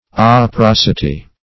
Operosity \Op`er*os"i*ty\